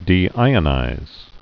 (dē-īə-nīz)